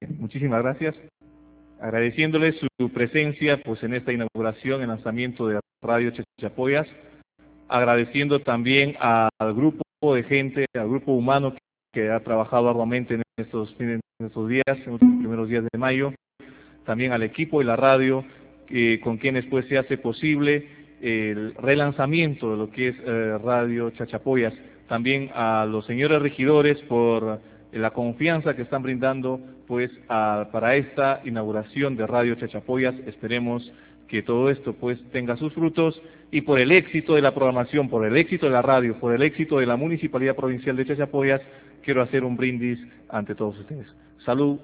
Quelle: Internetübertragung des ersten Programmes.
brindis_radio_chachapoyas.wma